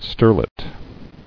[ster·let]